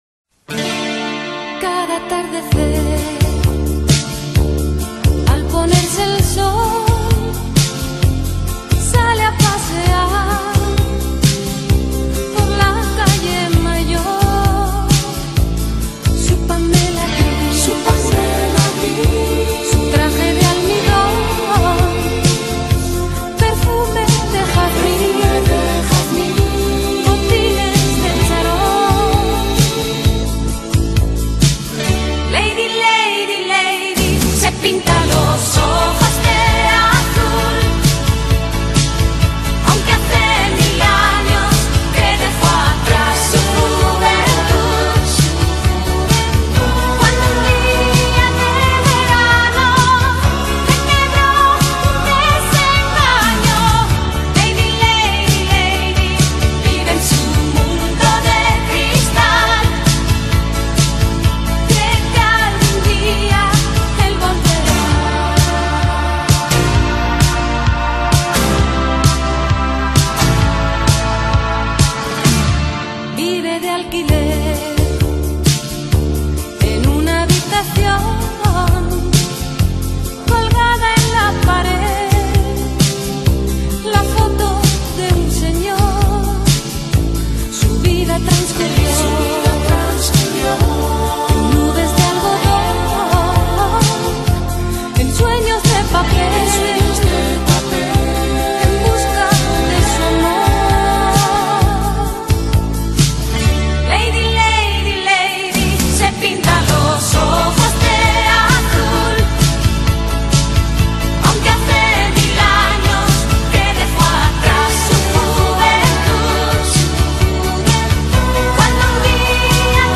آهنگ اسپانیایی